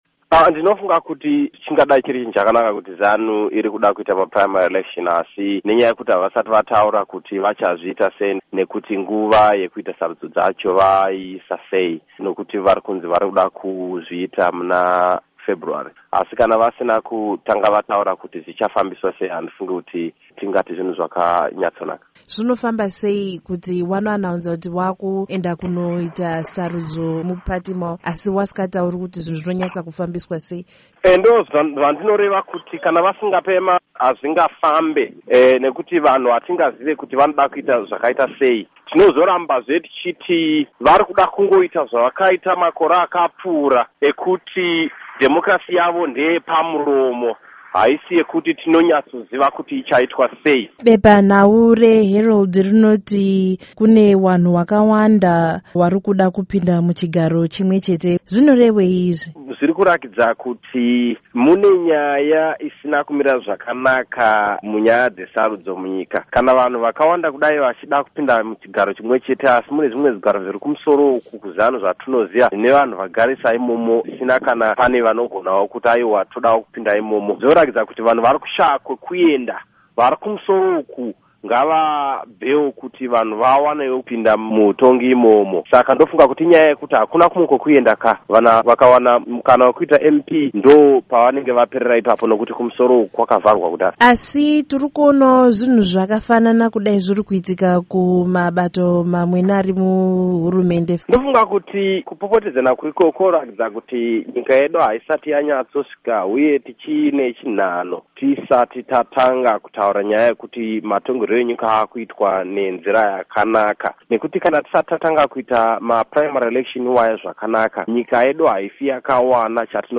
Interview With Eric Matinenga